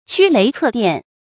驅雷策電 注音： ㄑㄩ ㄌㄟˊ ㄘㄜˋ ㄉㄧㄢˋ 讀音讀法： 意思解釋： 見「驅雷掣電」。